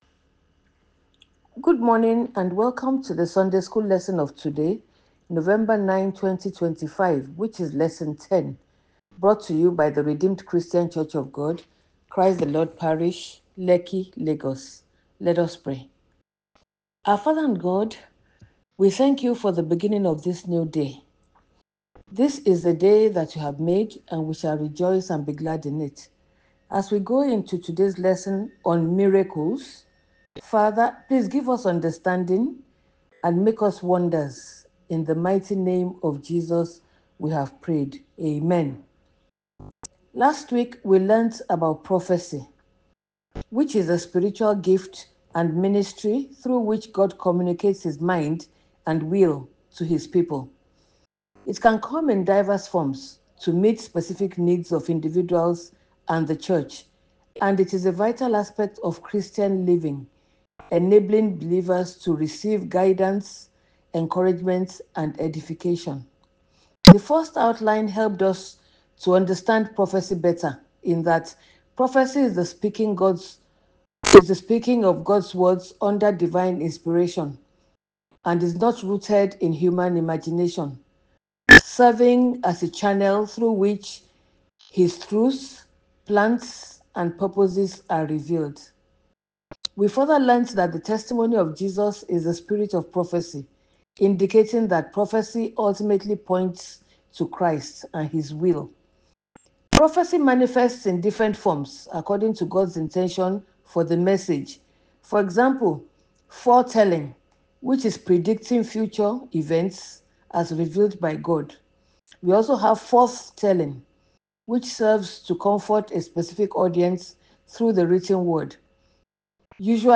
SUNDAY SCHOOL LESSON 10 – MIRACLES - RCCG Christ the lord
RCCG-CTL-SUNDAY-SCHOOL-Lesson-10-MIRACLES.ogg